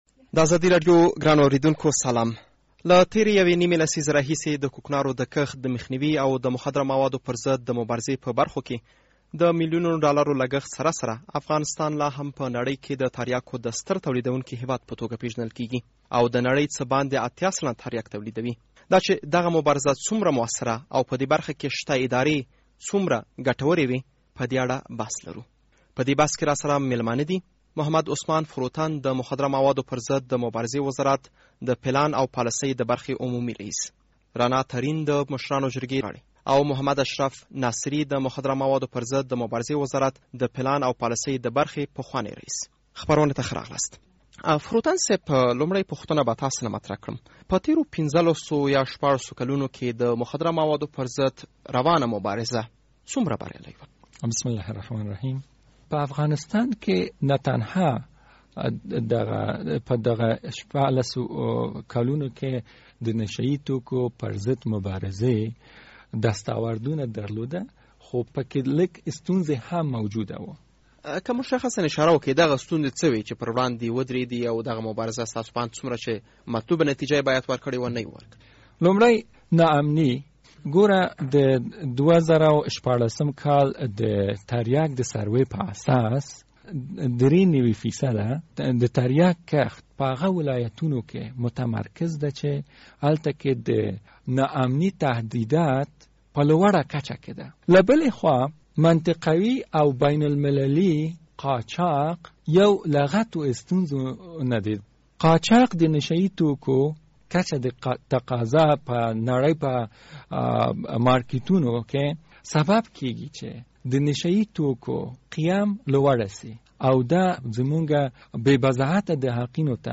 د زهرو کاروان پروګرام بحث